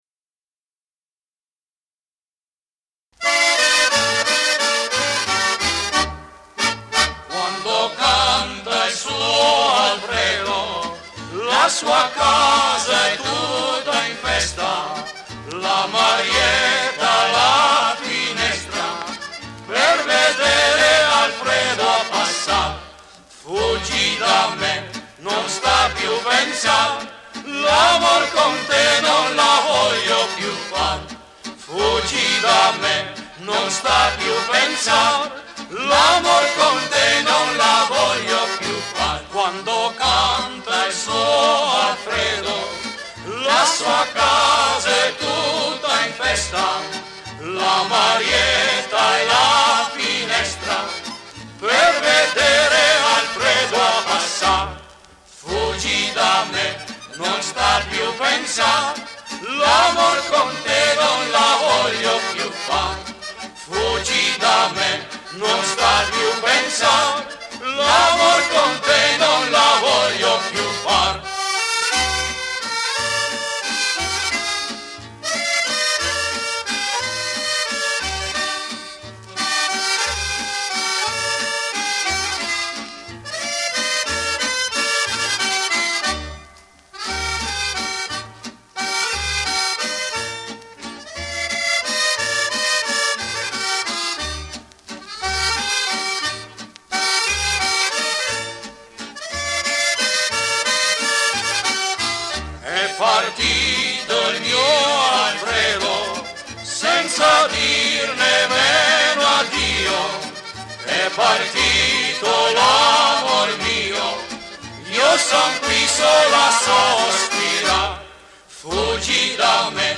SING-A-LONG OLD ITALIAN POPULAR SONGS